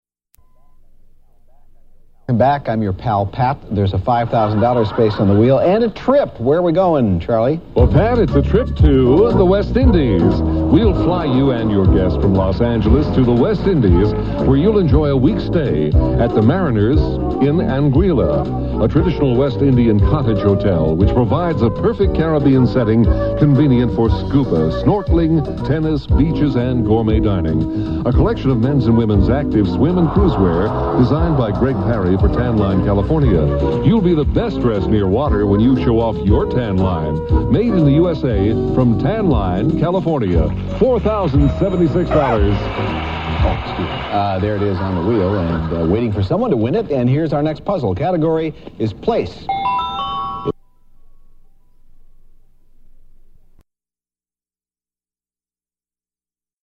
Excerpt from a "Wheel of Fortune" episode
• Audiocassette